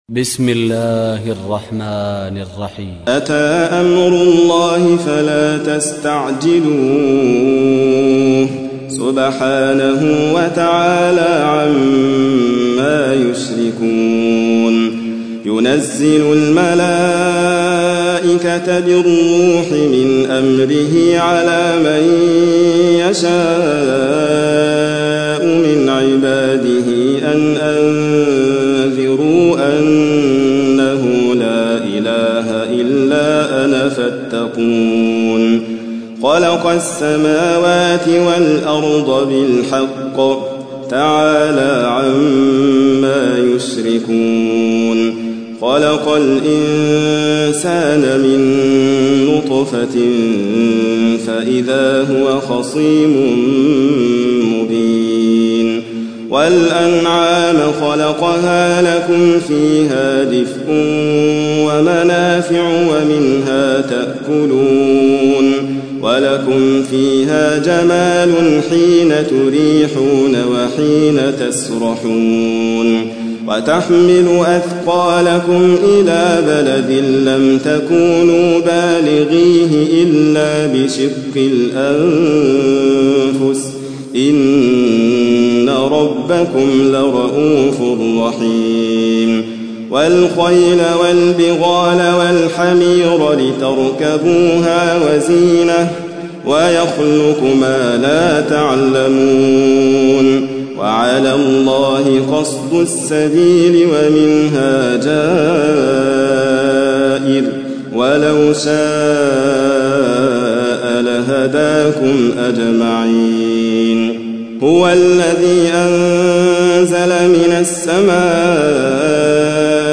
16. سورة النحل / القارئ